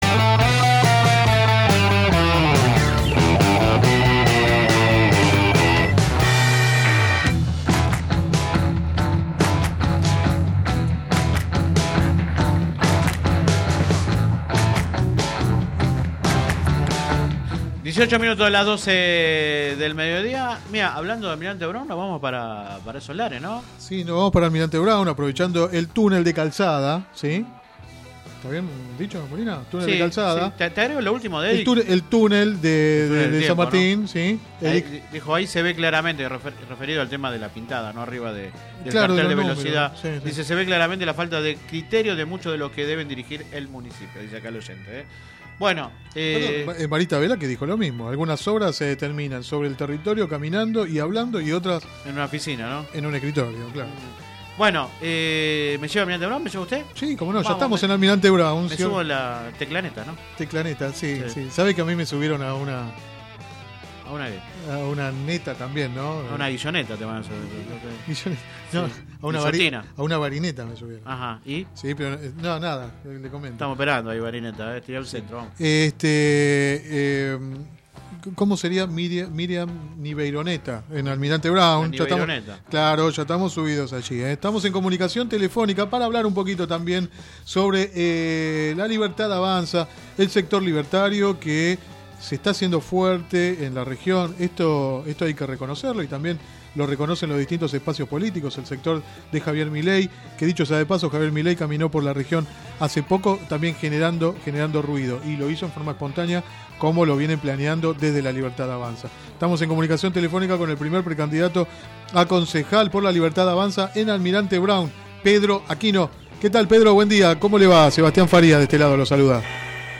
Click acá entrevista radial https